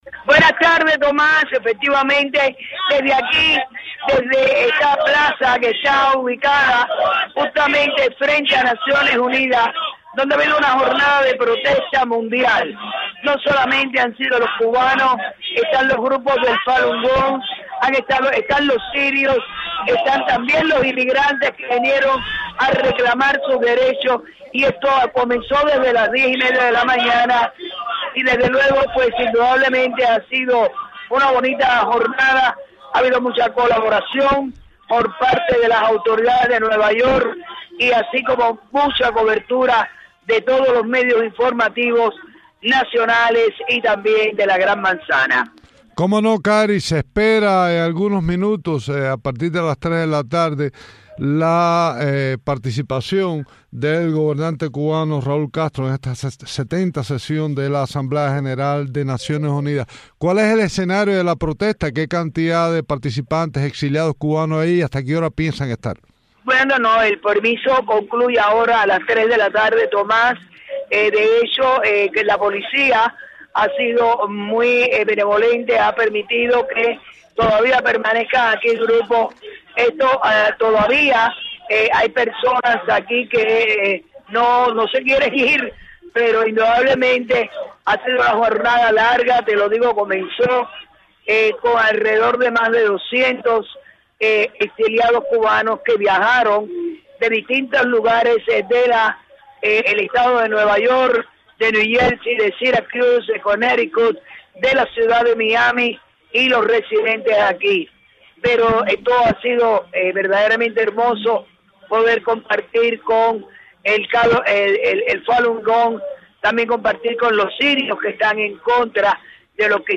Impresiones de activistas en la protesta